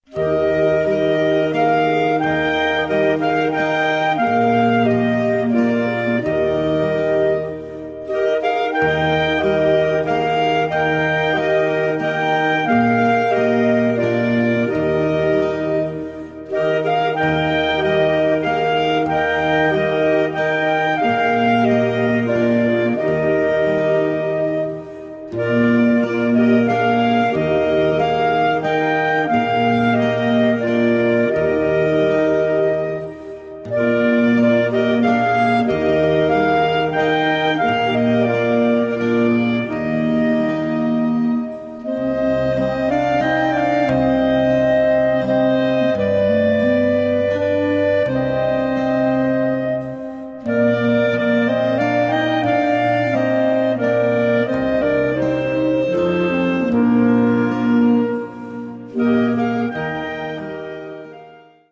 Weihnachtsmusik
Schwierigkeitsgrad: 1 (leicht)